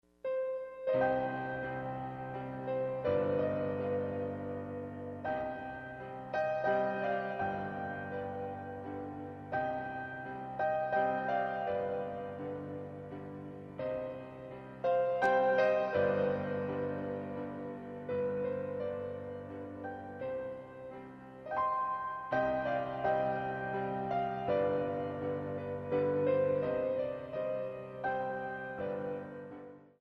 33 Piano Selections.